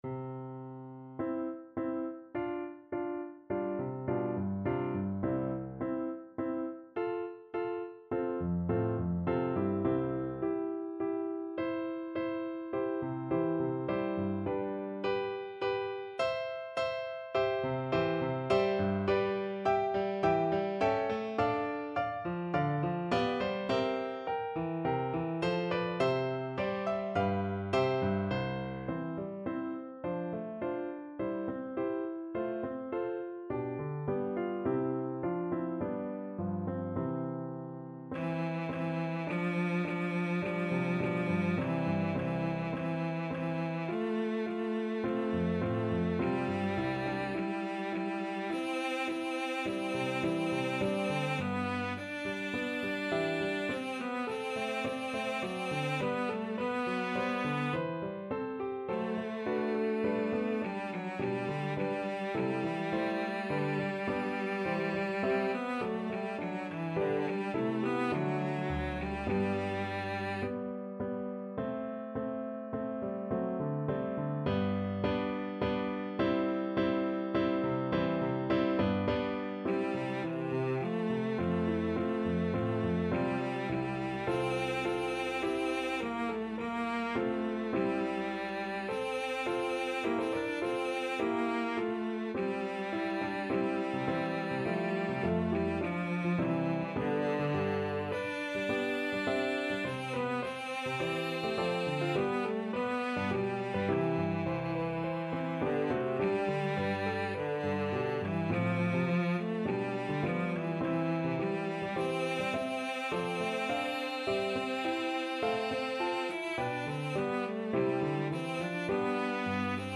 Classical Handel, George Frideric As With Rosy Steps the Morn from Theodora Cello version
Cello
C major (Sounding Pitch) (View more C major Music for Cello )
4/4 (View more 4/4 Music)
Larghetto (=c.52)
Classical (View more Classical Cello Music)